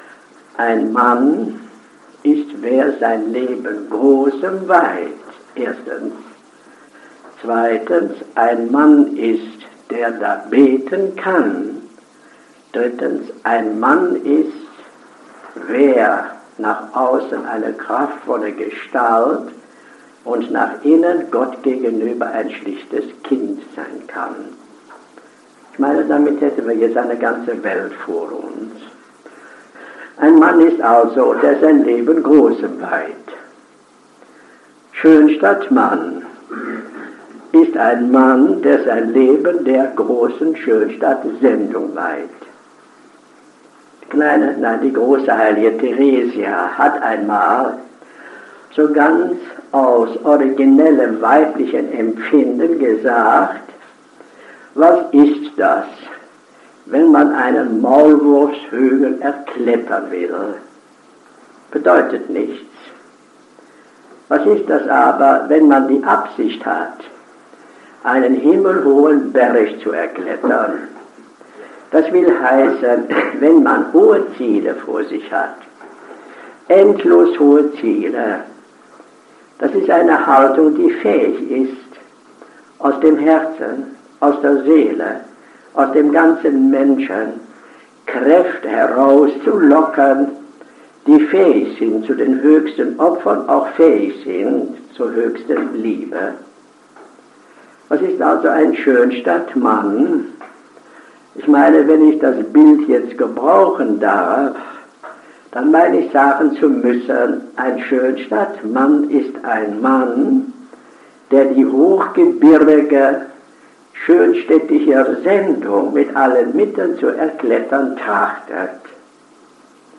Auszug_Einfuehrungskurs_fuer_Maenner1966.mp3